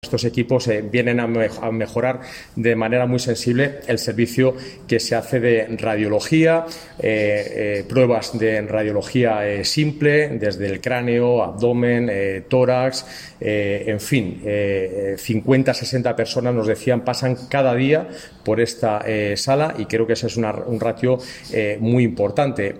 Declaraciones del delegado de la Junta en Guadalajara